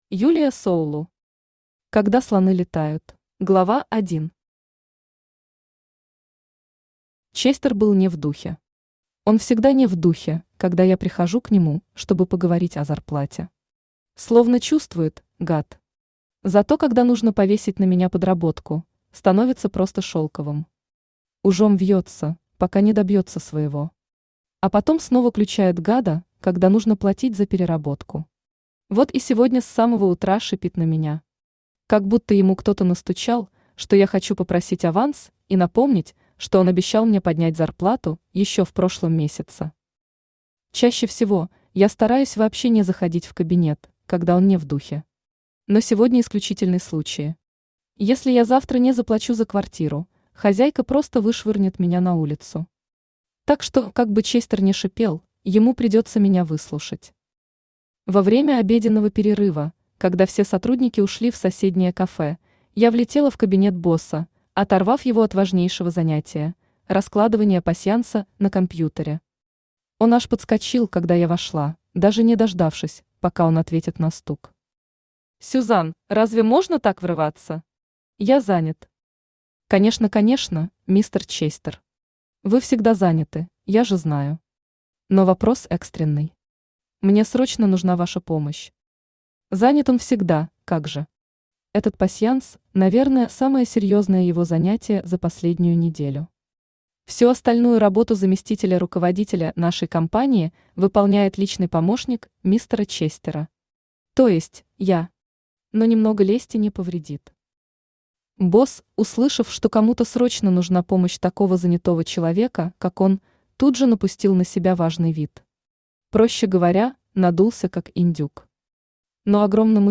Аудиокнига Когда слоны летают | Библиотека аудиокниг
Aудиокнига Когда слоны летают Автор Юлия Соулу Читает аудиокнигу Авточтец ЛитРес.